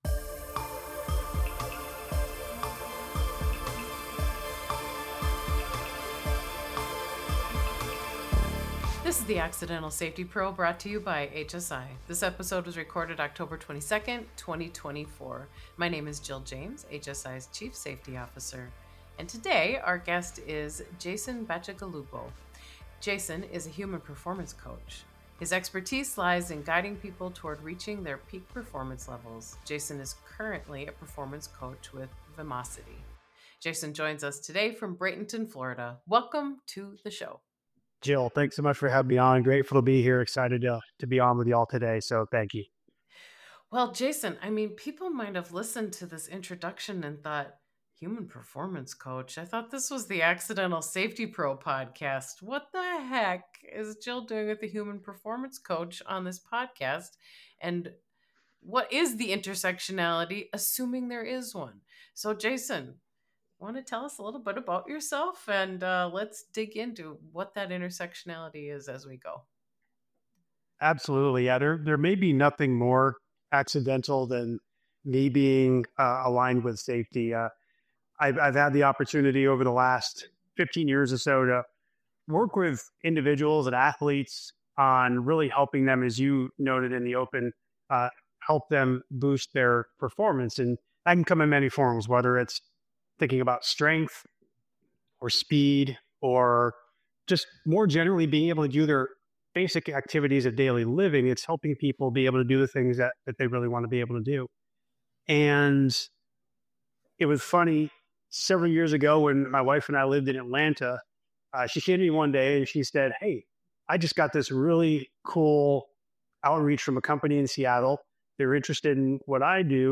This safety podcast is a series of conversations with safety professionals about how they came into their role, what they've learned along the way, as well as some of the highs and lows that come with job.